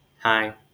wymowa: